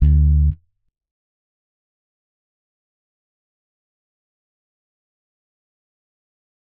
Bass Zion 3.wav